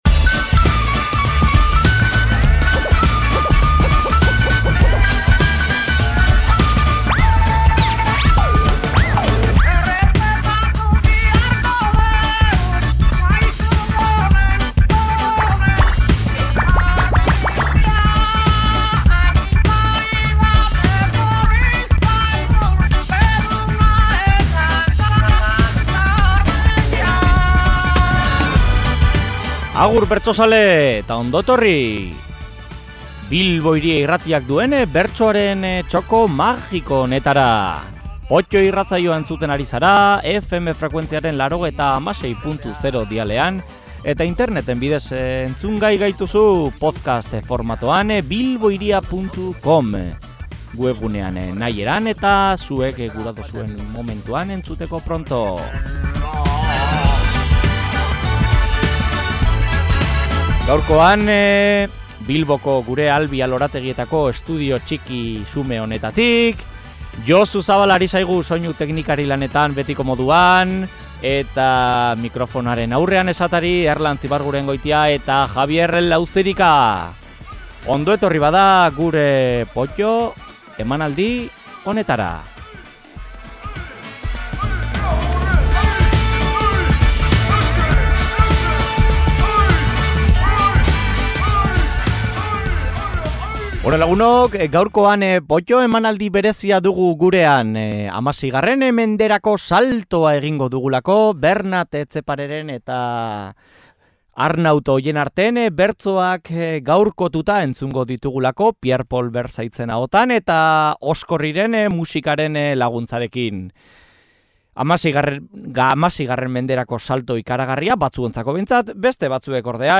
Saioa bukatzeko, duela gutxi bertso-saio pare baten zati batzuk entzun ditugu.